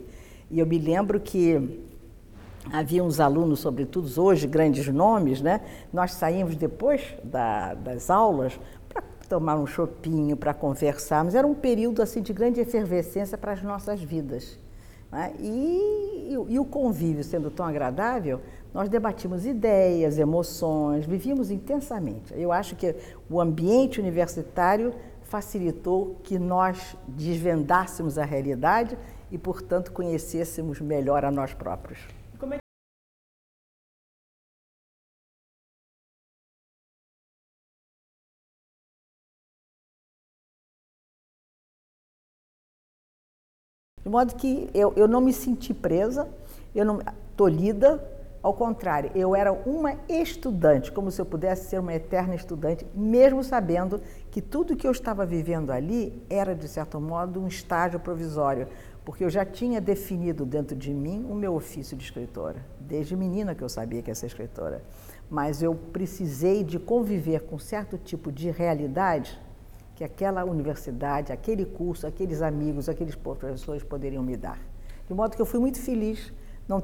Entrevistada pela TVPUC   IDÉIAS E EMOÇÕES
Jornalista e escritora, Nélida Piñon se formou em Comunicação (56).